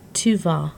Click each word to hear the pronunciation.